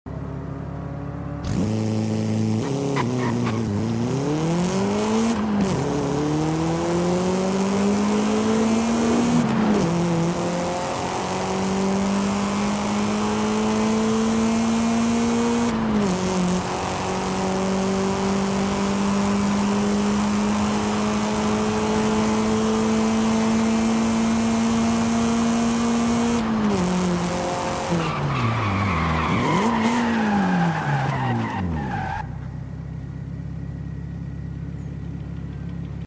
1994 Nissan Silvia K's Launch sound effects free download
1994 Nissan Silvia K's Launch Control & Sound - Forza Horizon 5